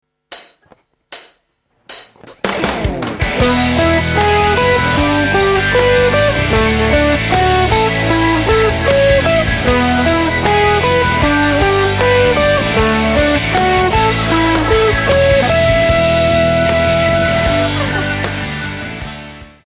In this guitar lesson the CAGED Chord System will be used over a 4 chord progression showing ideas to create a guitar solo based on arpeggios.
The chords in the example are E G D and A major which are the same chords used in the chorus of Alive by Pearl Jam and countless other songs.
Exercise 3 is based on the A major chord shape using 4 strings while shifting it up and down the neck for each chord.
CAGED A Chord Shape Arpeggios